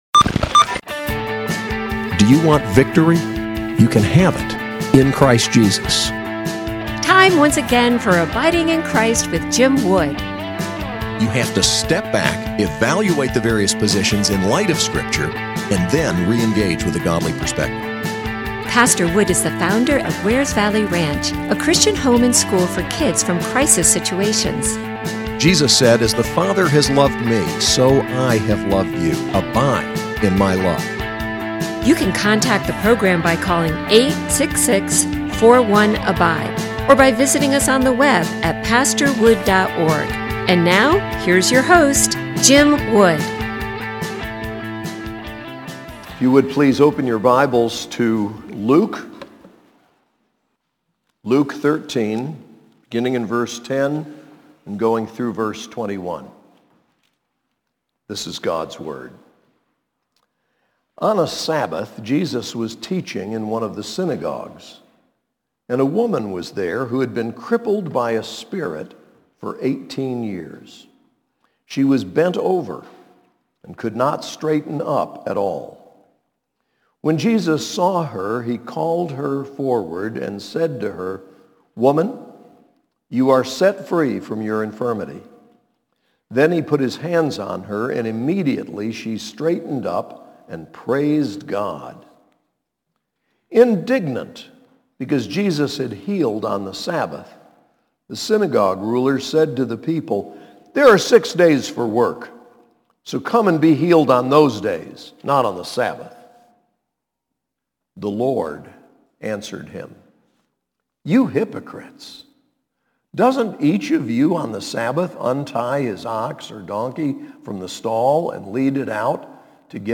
SAS Chapel: Luke 13:10-21